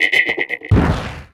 Catégorie:Cri Pokémon (Soleil et Lune) Catégorie:Cri de Tritox